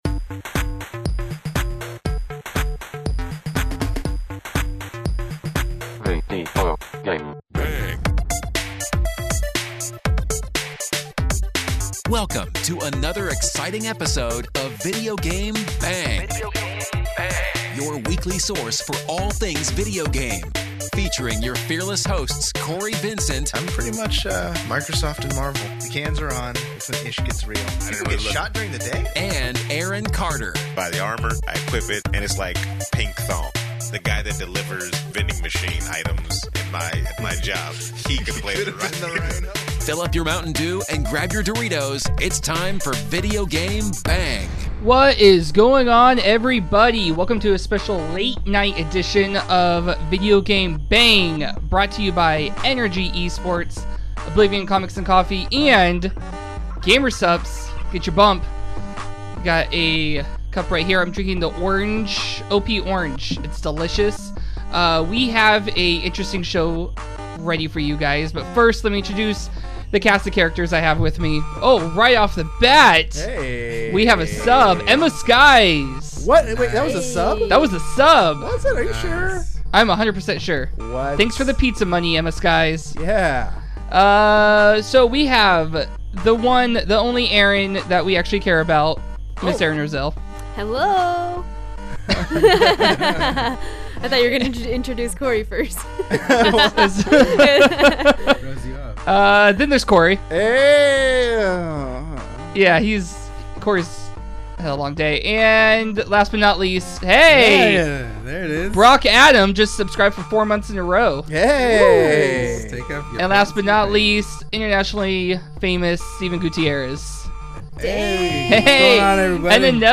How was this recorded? in studio to talk about the new Overwatch hero, Nintendo controversy & more.